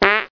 fart_anxious
fart sound effect free sound royalty free Memes